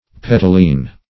Search Result for " petaline" : The Collaborative International Dictionary of English v.0.48: Petaline \Pet"al*ine\ (p[e^]t"al*[i^]n), a. [Cf. F. p['e]talin.]